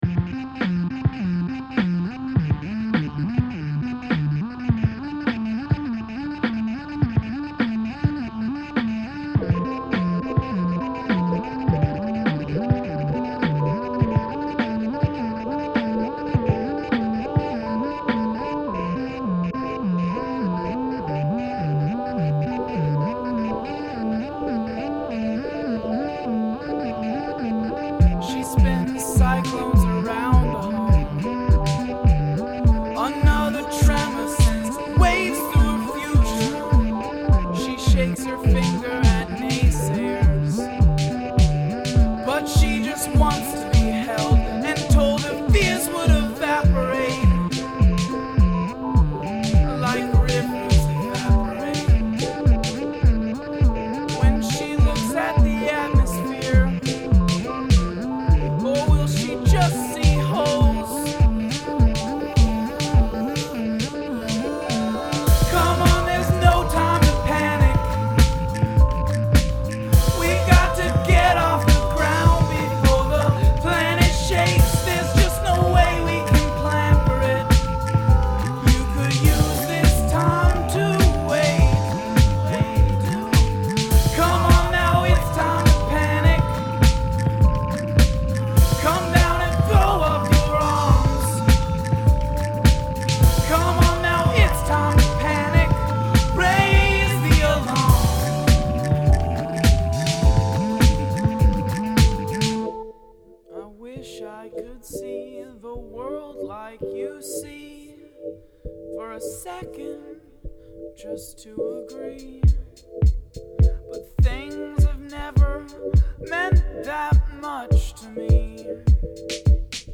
Song must include audible counting